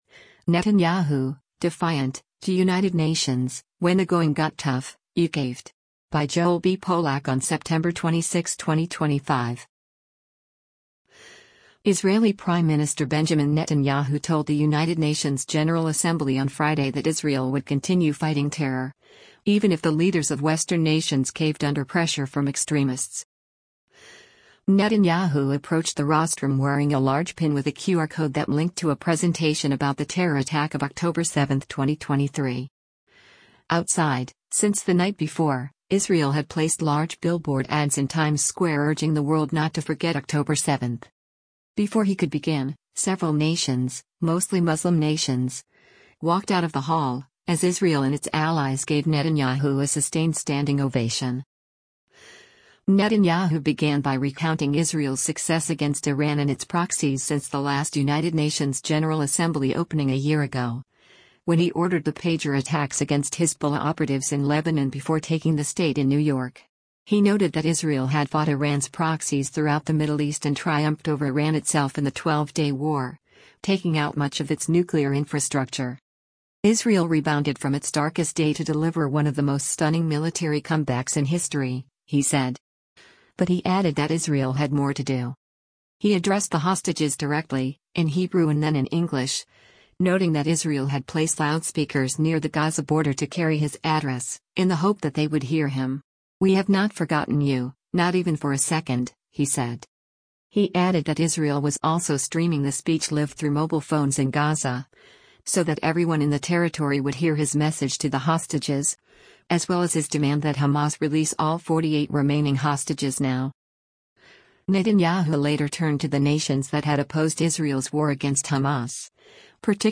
Israel Prime Minister Benjamin Netanyahu addresses the 80th session of the United Nations
Before he could begin, several nations — mostly Muslim nations — walked out of the hall, as Israel and its allies gave Netanyahu a sustained standing ovation.
He addressed the hostages directly, in Hebrew and then in English, noting that Israel had placed loudspeakers near the Gaza border to carry his address, in the hope that they would hear him.